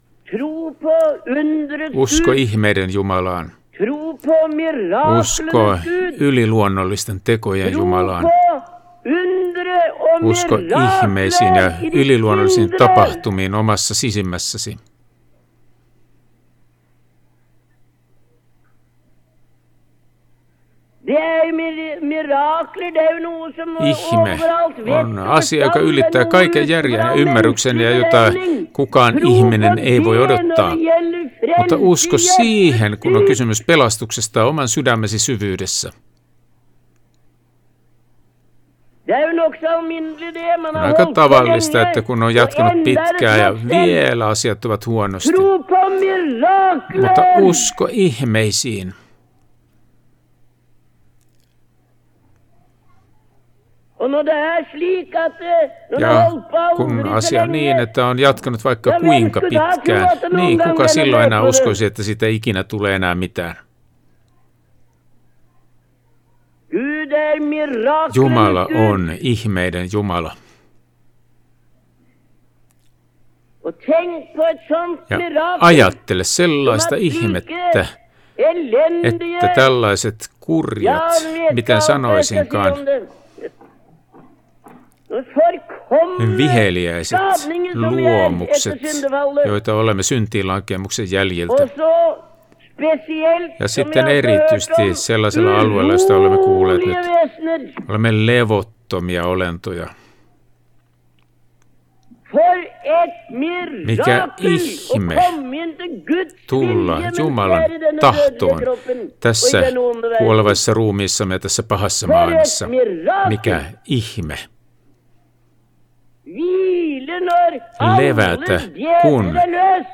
Puheesta vuonna 1965